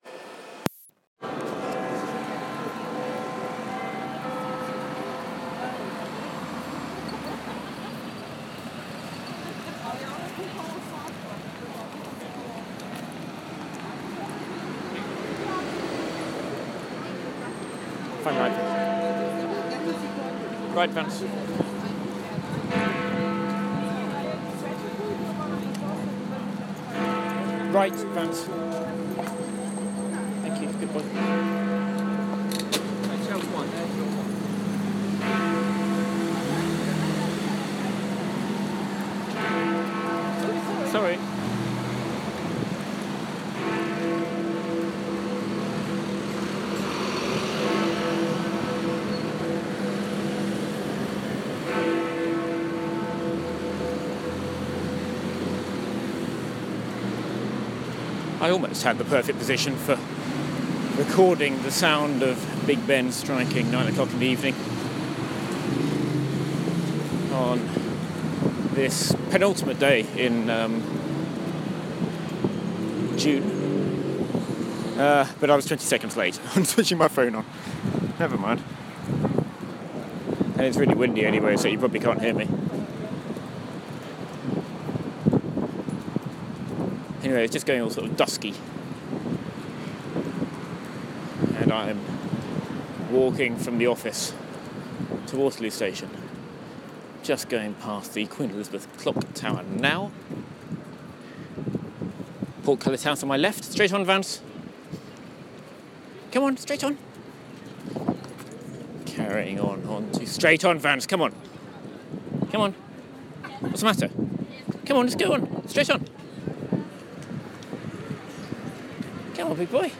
walking on Westminster Bridge